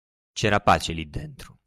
Frequency A1 Pronounced as (IPA) /ˈli/ Etymology From Latin illic.